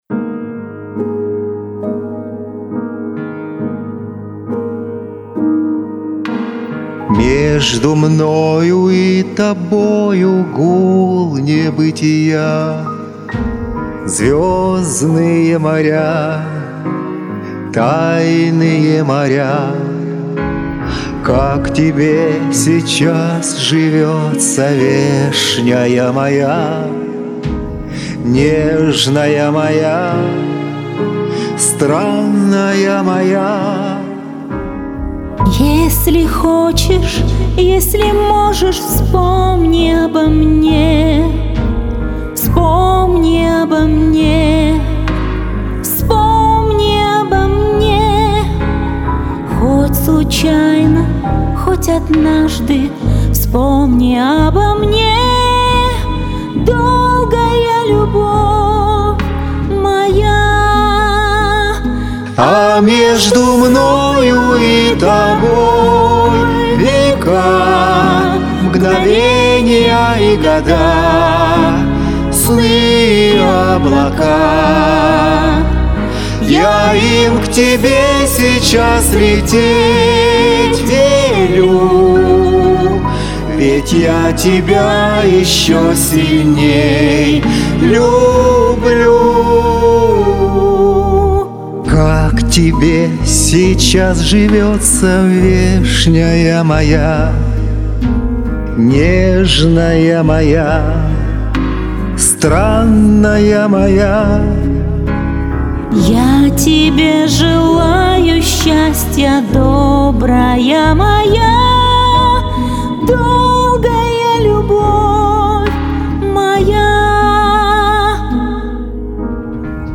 Вот и появилась мысль про 2, Но спели то очень ВМЕСТЕ!